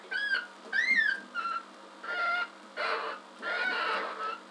Звучание крупного пёструшка (мужской)